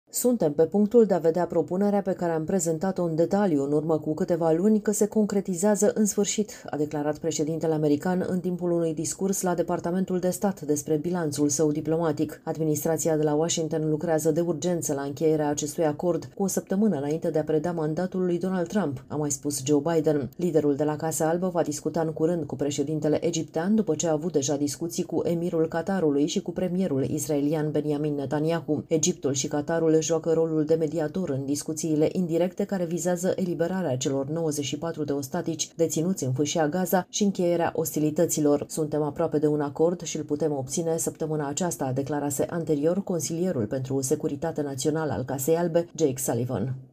„Suntem pe punctul de a vedea o propunere, pe care am prezentat-o în detaliu în urmă cu câteva luni, că se concretizează în sfârşit”, a declarat preşedintele american în timpul unui discurs la Departamentul de Stat despre bilanţul său diplomatic.